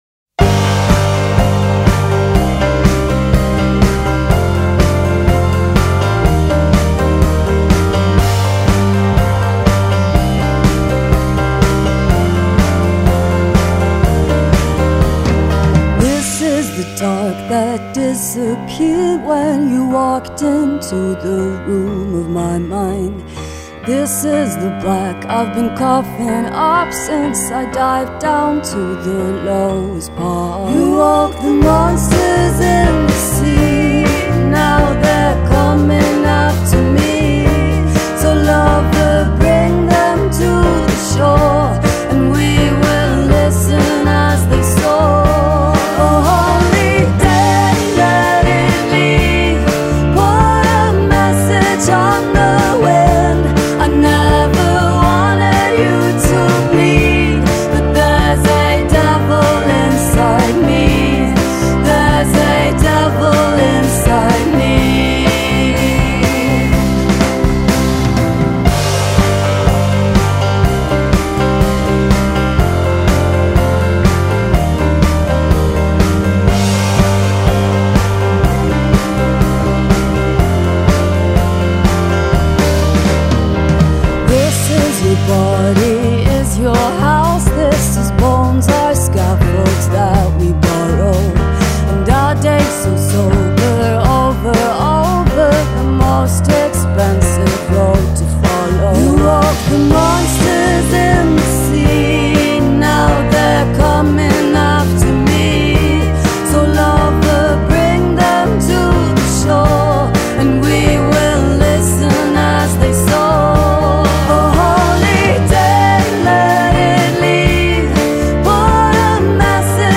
Indie / Rock.
has a more raucous timbre
slightly sinister lyrics with rolling piano clusters.